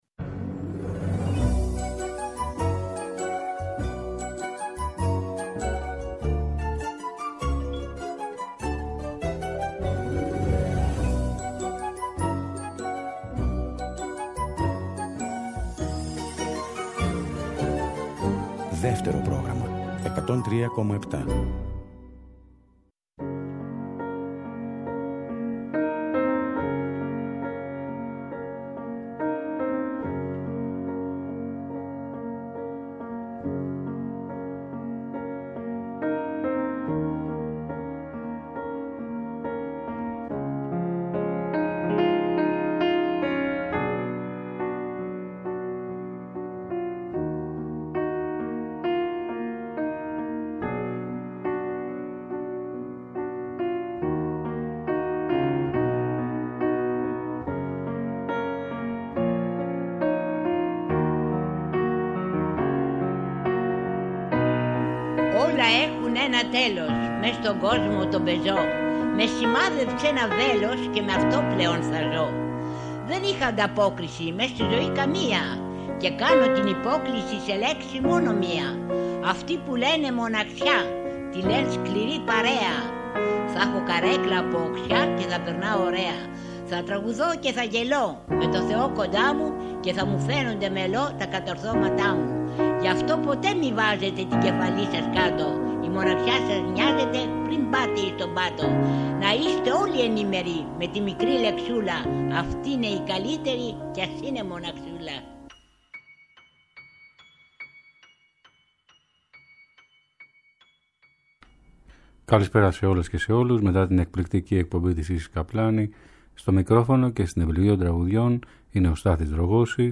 Ποιήματα, ιστορίες και φθινοπωρινές ελεγείες. Τραγούδια αγαπησιάρικα, μελαγχολικά και γλυκά!